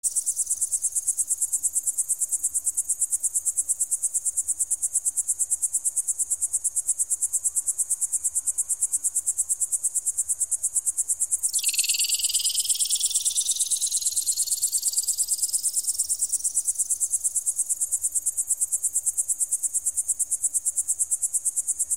Звуки пугала: звук для отпугивания птиц с огорода (электронное пугало)